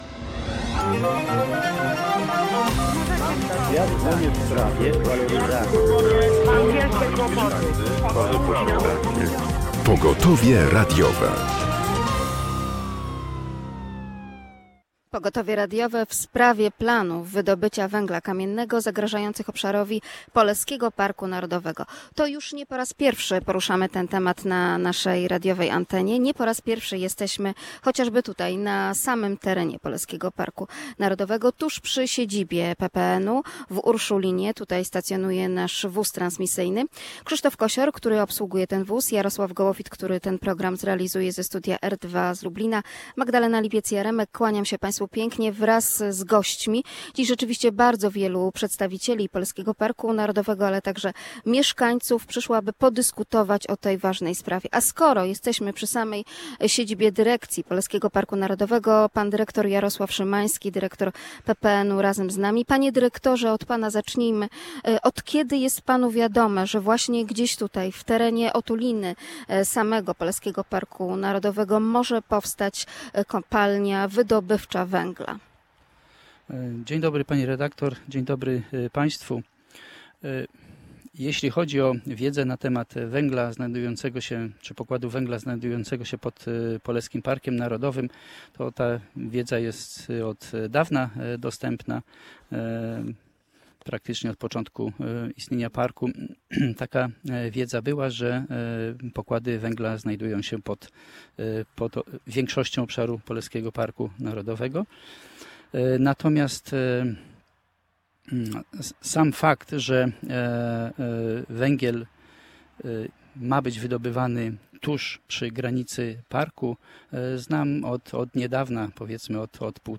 Mieszkańcy gmin Hańsk, Urszulin, Sawin, Cyców i Wierzbica proszą „Pogotowie radiowe” o zadeklarowanie poparcia dla ochrony przyrody i jakości życia mieszkańców przed zagrożeniami związanymi z planowaną kopalnią węgla kamiennego w pobliżu Poleskiego Parku Narodowego. Dziś wóz transmisyjny Polskiego Radia Lublin stanie przy siedzibie PPN w Urszulinie. Wysłuchamy racji mieszkańców i różnych organizacji zaangażowanych w sprawę.